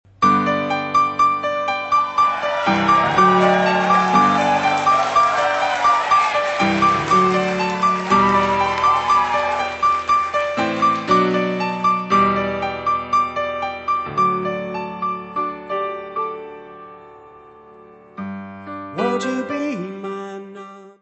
piano
baixo
bateria.
Music Category/Genre:  Pop / Rock